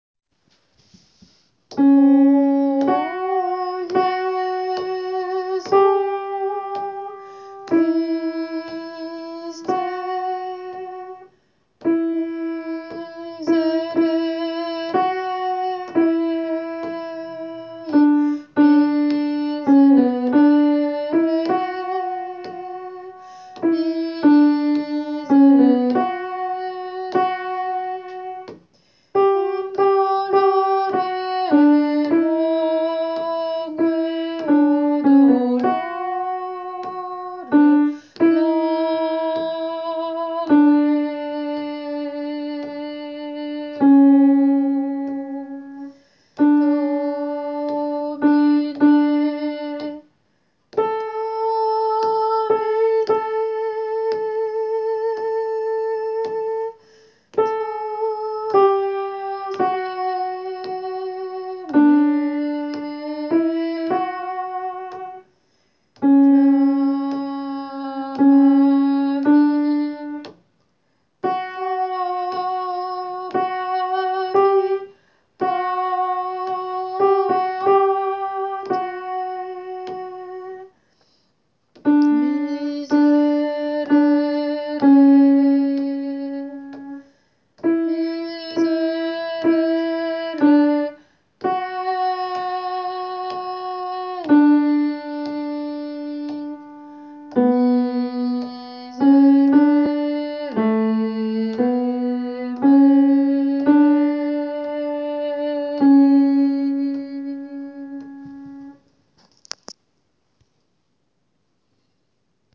Alto :
jesu-christe-alto.wav